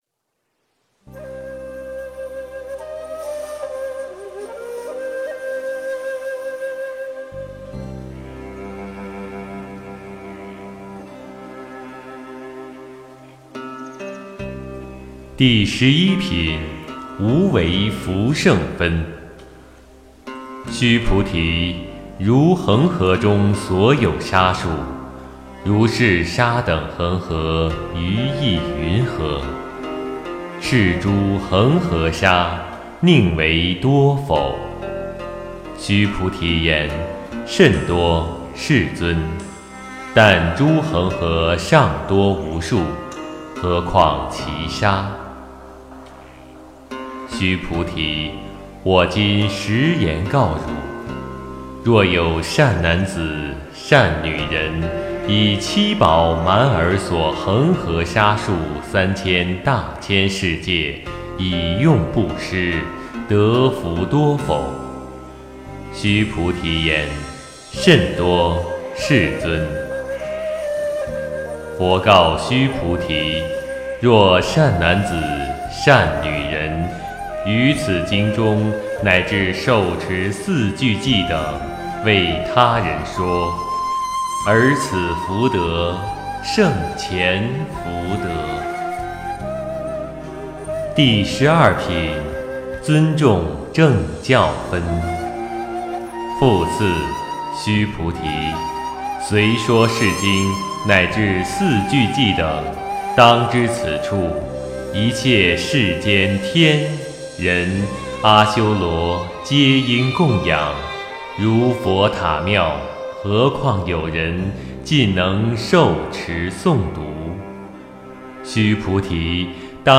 诵经
佛音 诵经 佛教音乐 返回列表 上一篇： 金刚经：第九品和第十品 下一篇： 金刚经：第二十三品和第二十四品 相关文章 修人和世俗人--翻唱佛歌 修人和世俗人--翻唱佛歌... 84.何谓净土--佚名 84.何谓净土--佚名...